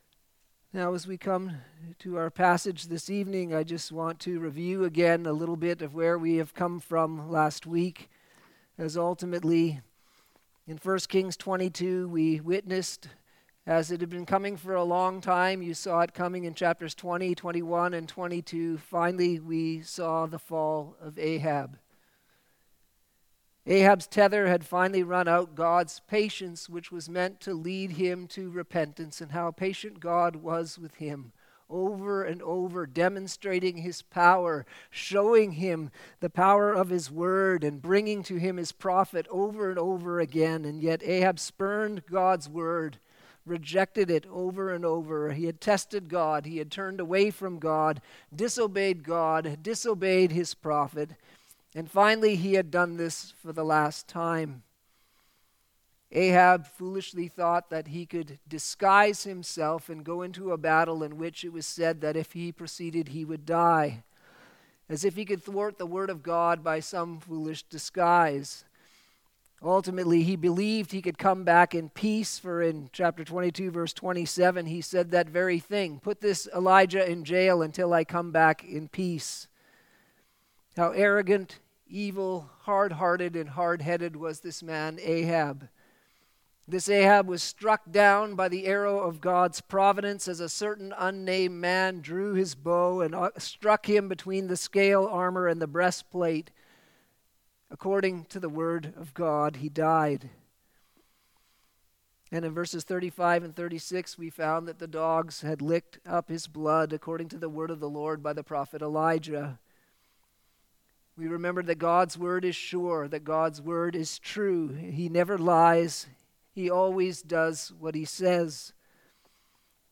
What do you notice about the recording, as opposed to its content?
Sunday Evening Service - 2 Kings 1:1-17